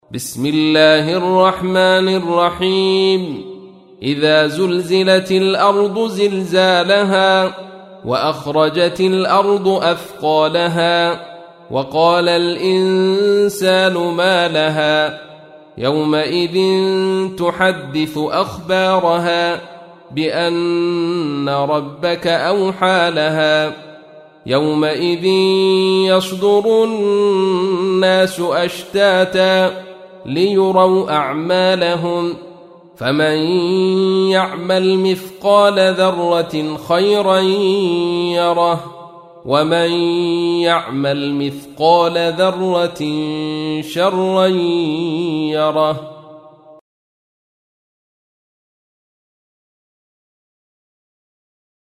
تحميل : 99. سورة الزلزلة / القارئ عبد الرشيد صوفي / القرآن الكريم / موقع يا حسين